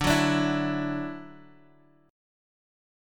Dadd9 chord